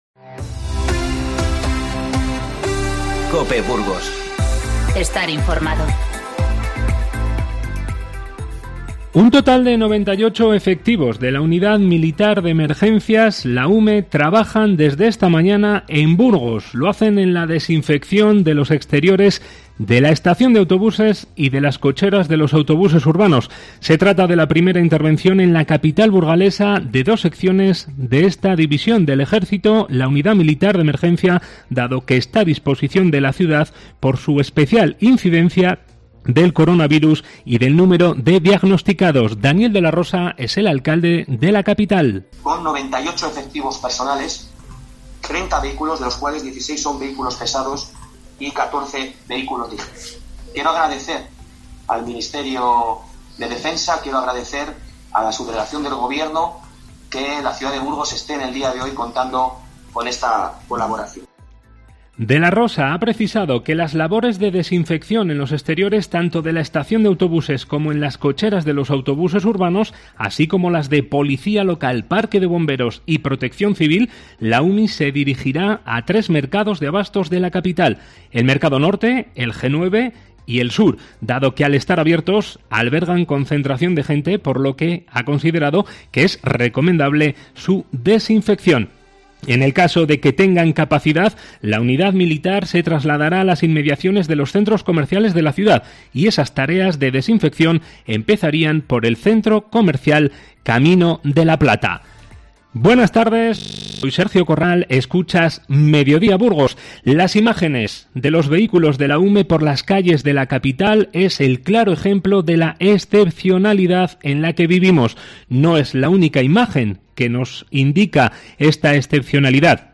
Informativo 16-03-20